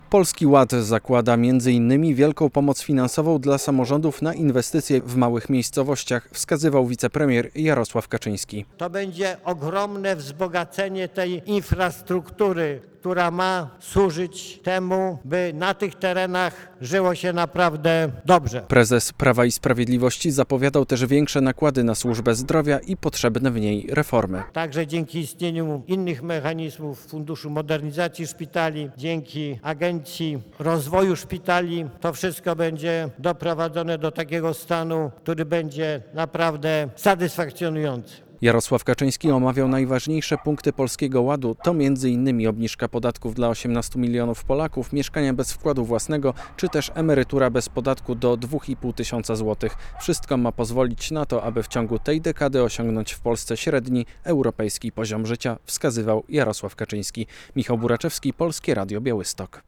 Polski Ład ma pozwolić na dynamiczny rozwój małych miejscowości - relacja